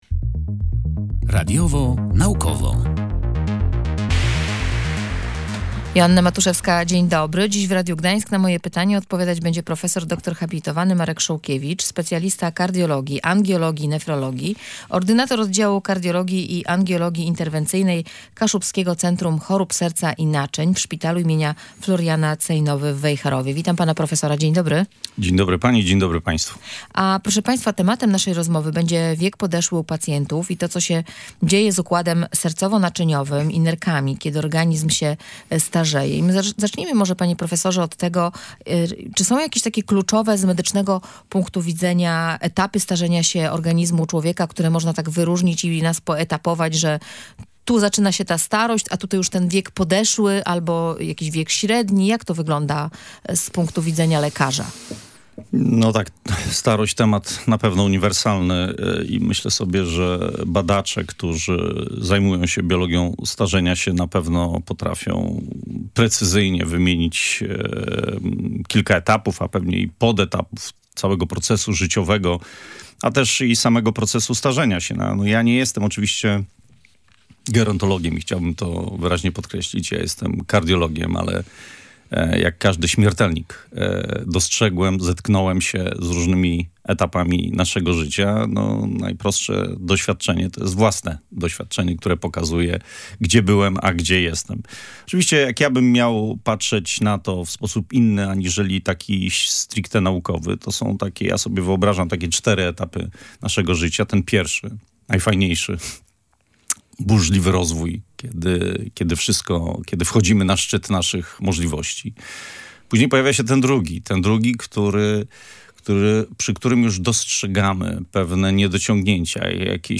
Wiek podeszły u pacjentów i to, co dzieje się układem sercowo-naczyniowym i nerkami, kiedy organizm się starzeje – między innymi o tym rozmawialiśmy w audycji „Radiowo-Naukowo”.